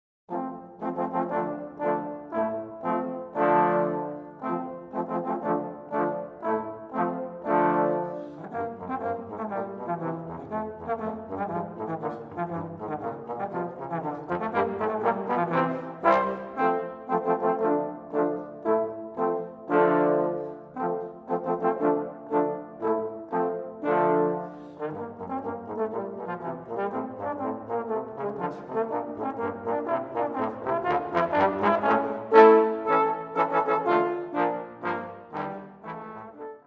For Trombone Quartet